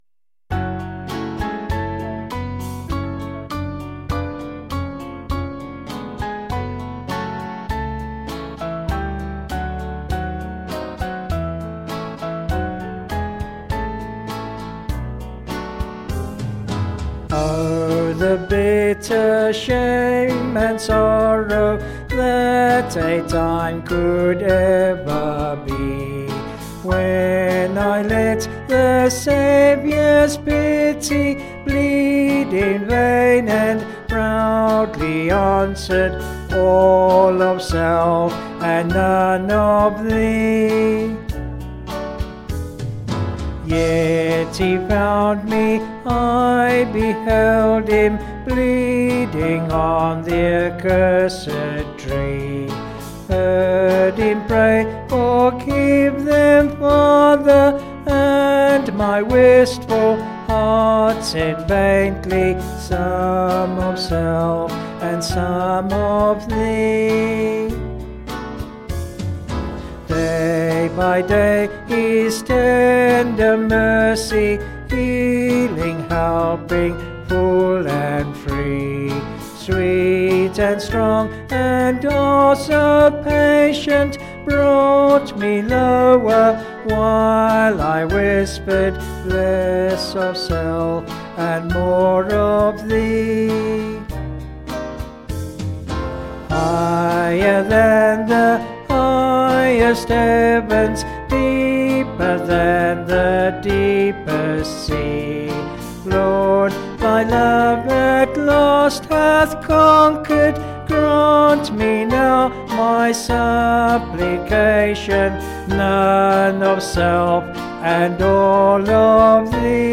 Vocals and Band   264.5kb Sung Lyrics